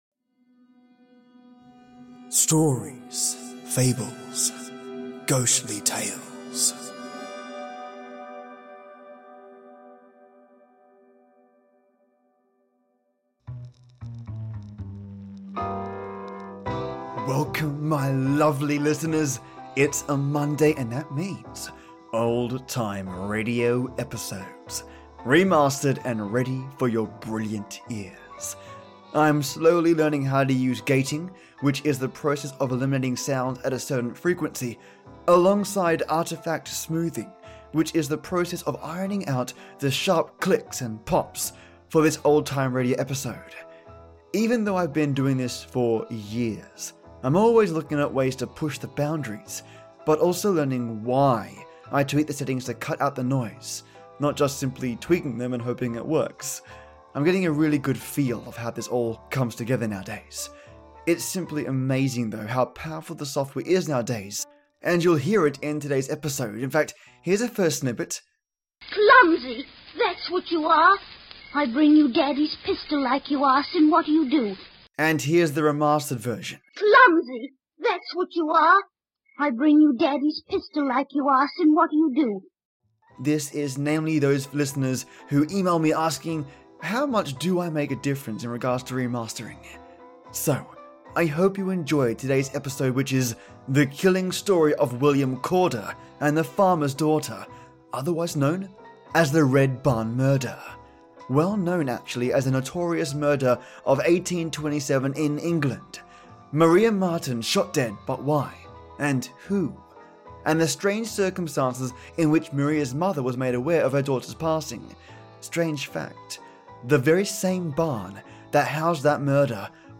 Welcome my lovely listeners, it’s Monday and that means Old Time Radio episodes. Remastered and ready for your brilliant ears. I’m slowly learning how to use Gating (which is the process of eliminating sounds at a certain frequency) along side Artifact Smoothing, (which is the process of ironing out the sharp clicks and pops) for this Old Time Radio Episodes.